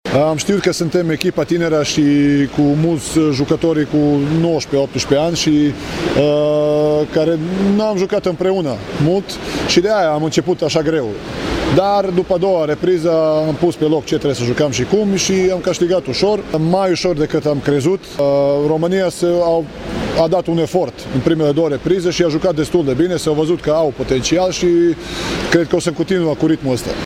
Portarul Serbiei, Gojko Pjetlovic, care joacă și la CSM Digi Oradea, a vorbit despre tinerețea echipei care a reprezentat Serbia.